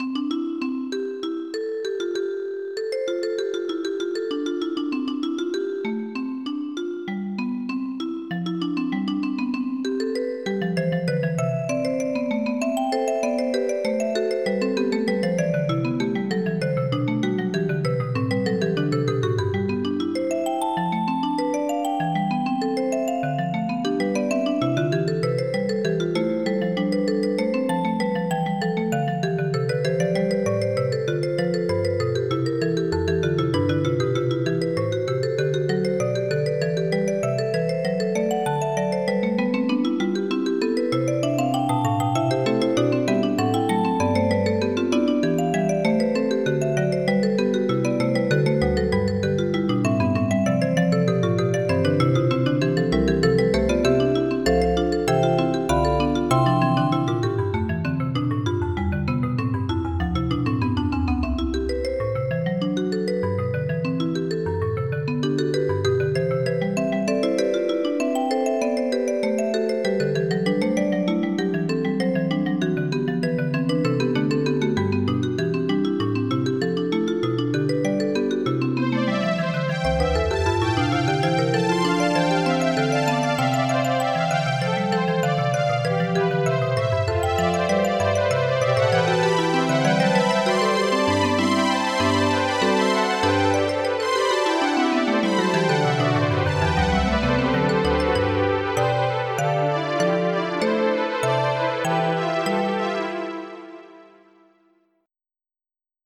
Type General MIDI